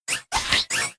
Audio / SE / Cries / APPLIN.ogg